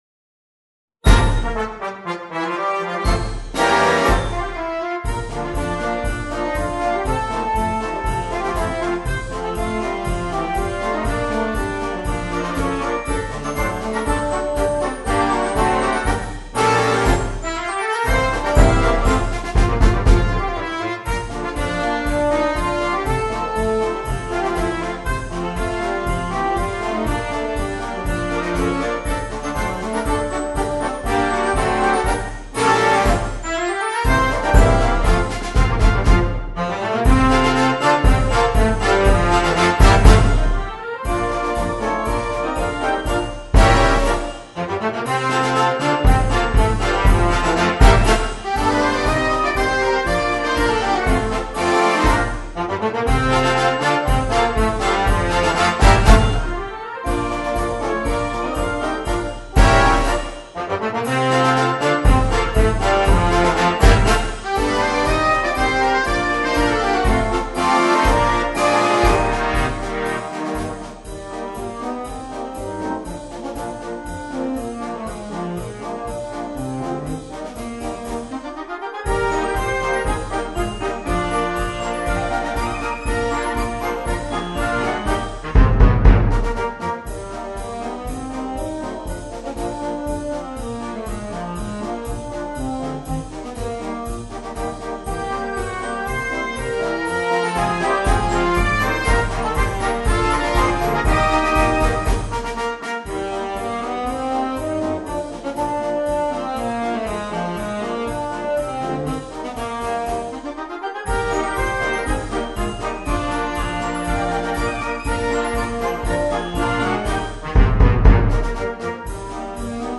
Marcia per banda